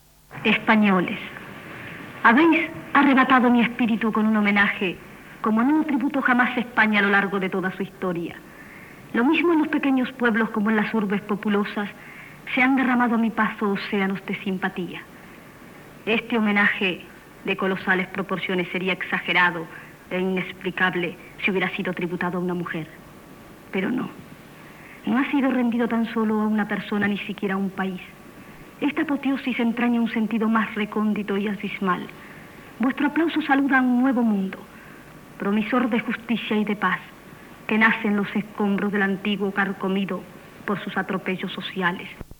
Paraules de la primera dama argentina Eva Duarte de Perón, pronunciades al Palau de Pedralbes de Barcelona, el dia final de la seva visita a Espanya
Informatiu